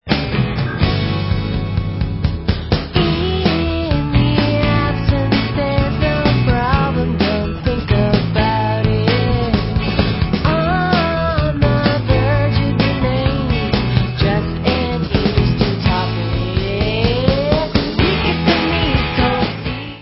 sledovat novinky v oddělení Alternativní hudba